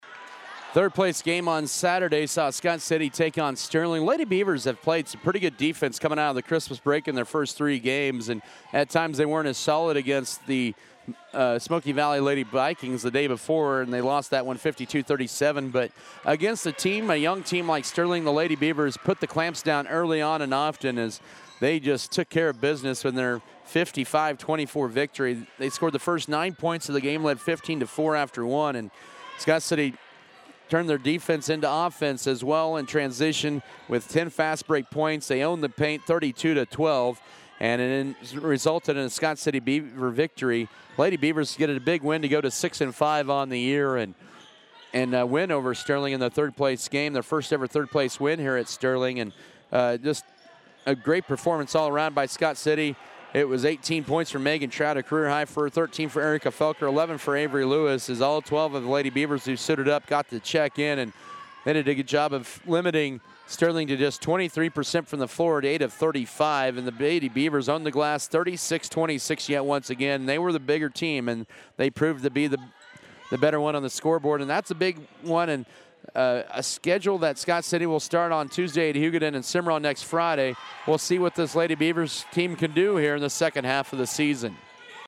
Girls Audio Recap